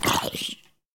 sounds / mob / zombie / hurt1.mp3
hurt1.mp3